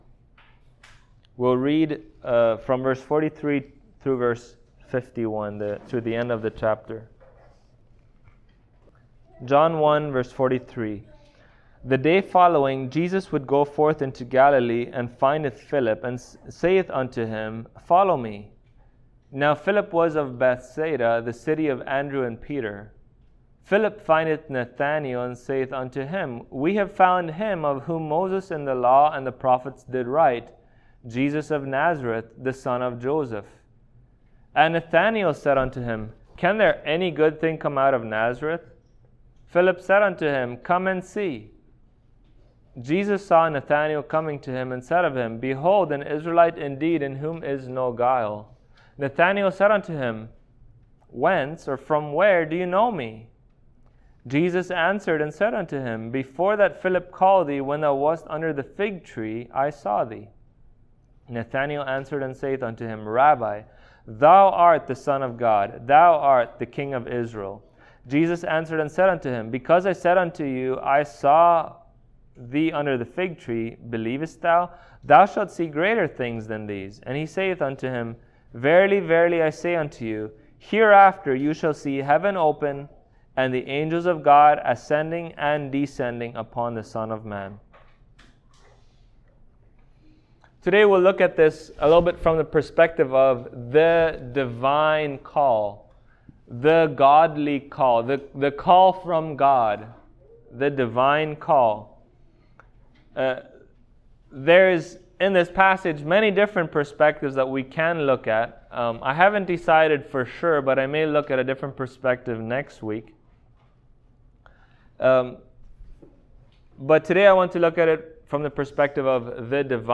John Passage: John 1:43-51 Service Type: Sunday Morning Topics